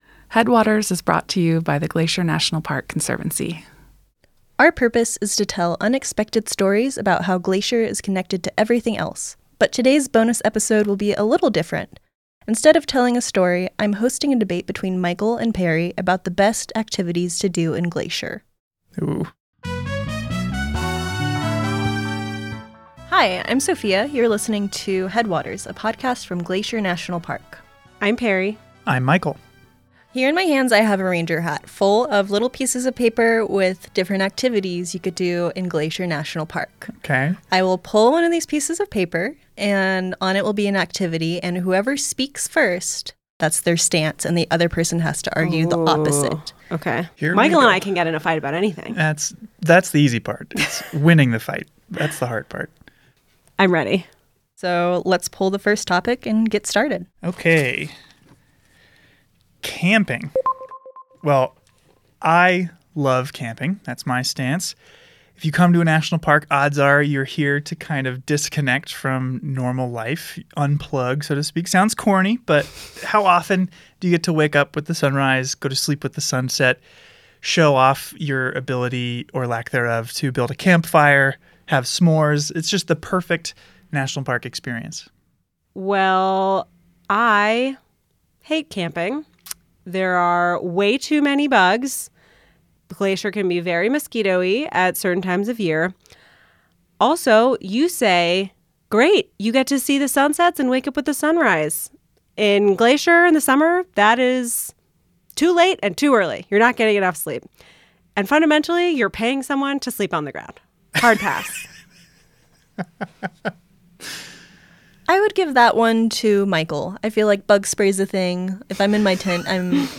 [Gameshow music starts, then fades to background]
[Paper rusting sounds] Okay.
[Electronic selection sound] Well, I love camping.